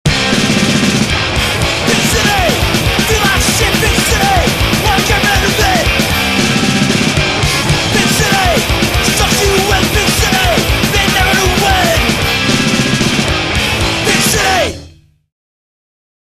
Punkhardcore da Roma